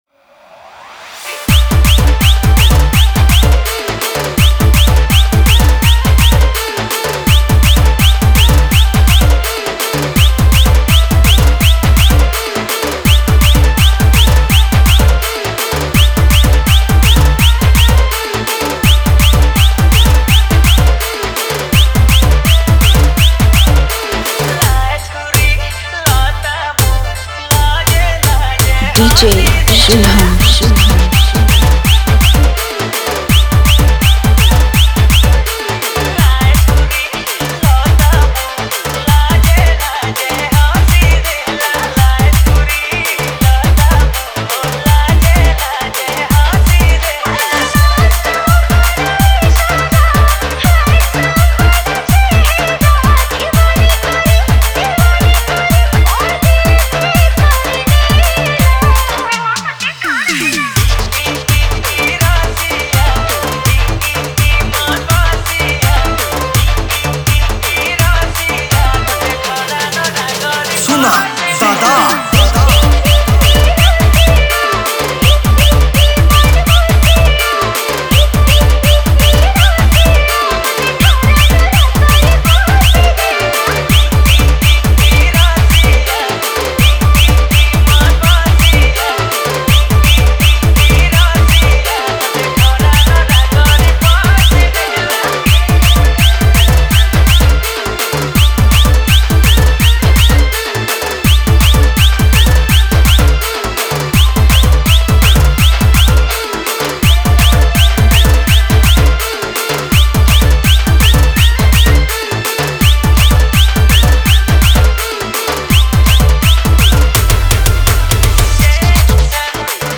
Category: Rajo Special DJ Song 2021